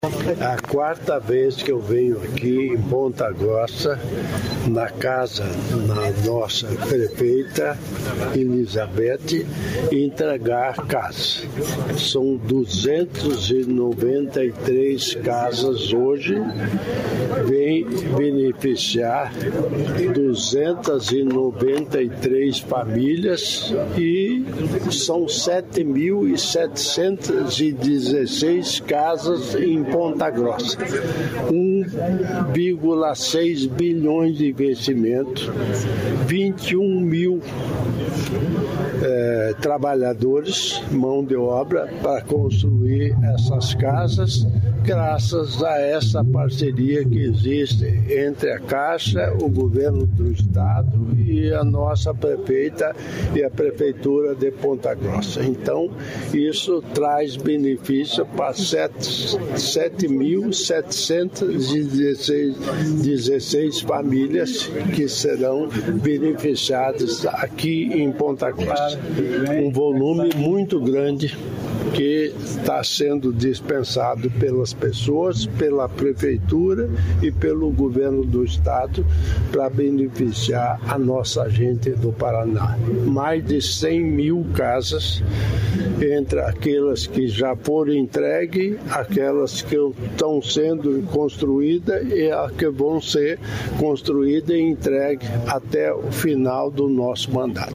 Sonora do governador em exercício Darci Piana sobre bairro planejado de Ponta Grossa